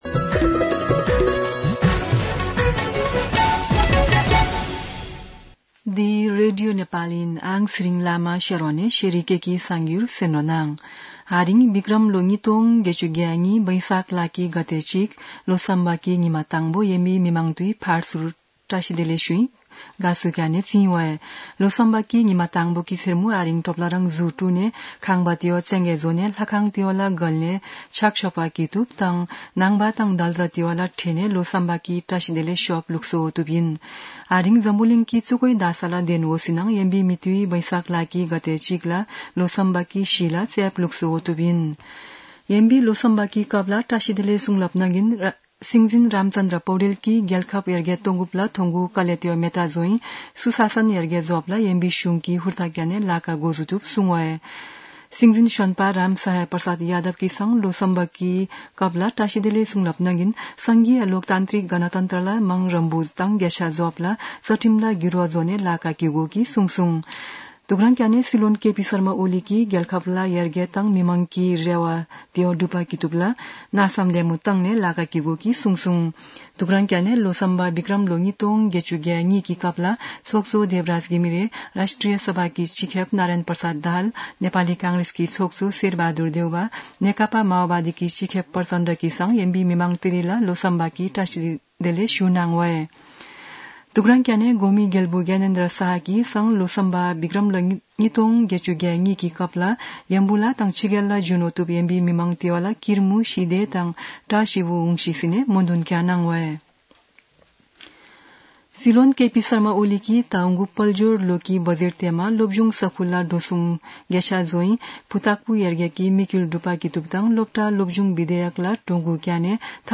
शेर्पा भाषाको समाचार : १ वैशाख , २०८२
Sherpa-News-4.mp3